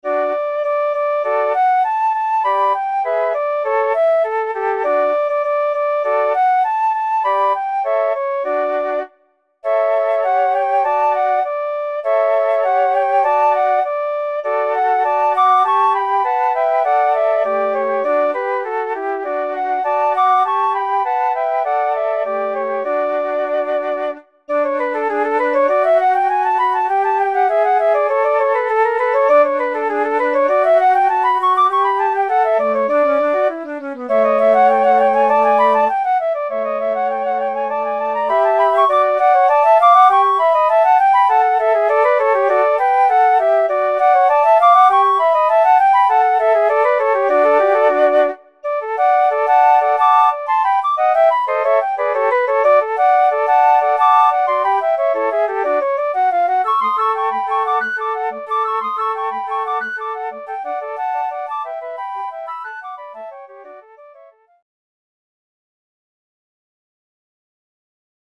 Flute Trio: 2 Fls, Afl.